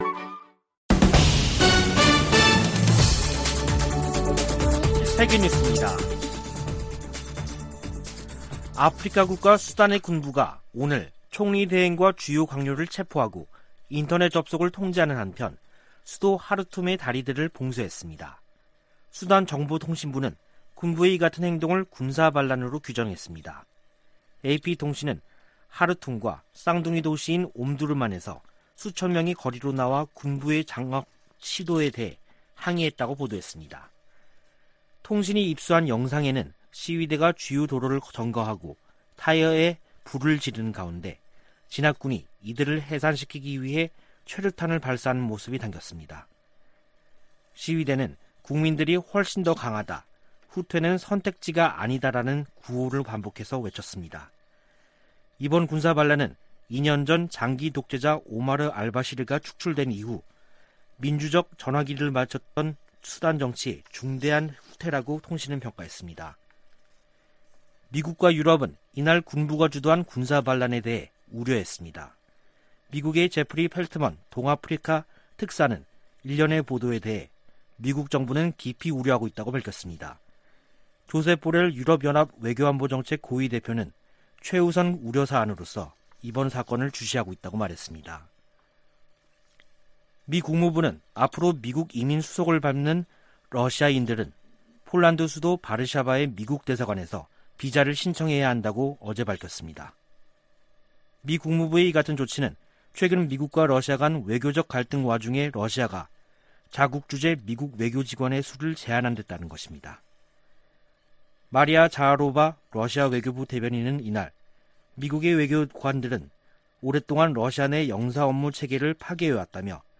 VOA 한국어 간판 뉴스 프로그램 '뉴스 투데이', 2021년 10월 25일 3부 방송입니다. 성 김 미 대북특별대표는 한반도 종전선언 등 관여 방안을 계속 모색하겠다면서도 북한의 탄도미사일 발사를 도발이라고 비판했습니다. 제76차 유엔총회에 북한 핵과 탄도미사일 관련 내용이 포함된 결의안 3건이 발의됐습니다. 북한이 플루토늄 추출과 우라늄 농축 등 핵 활동을 활발히 벌이고 있다는 우려가 이어지고 있습니다.